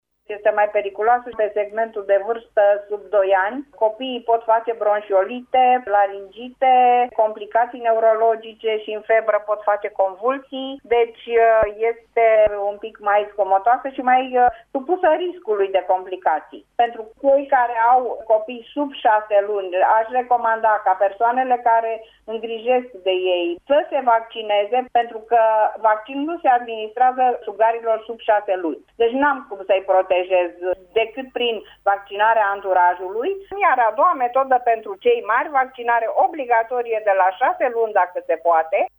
a explicat la Radio România Actualităţi, ce consecinţe poate avea gripa asupra copiiilor mici: